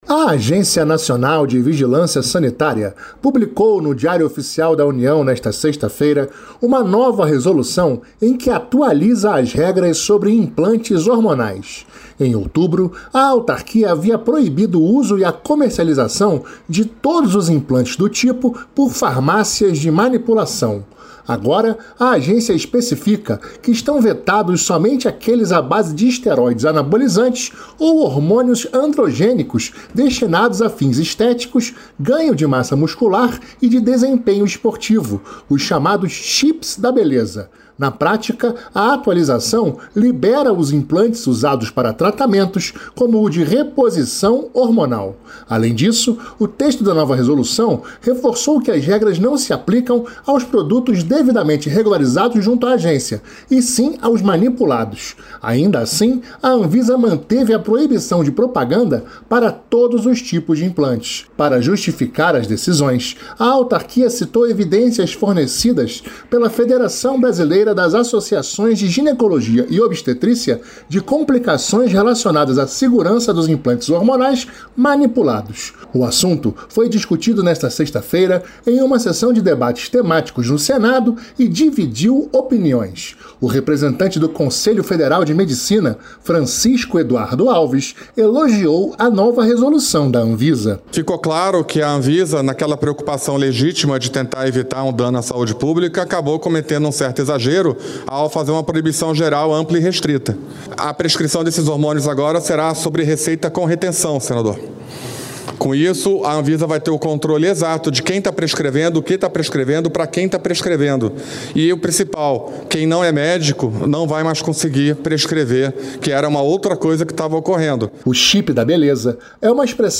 Sessão Temática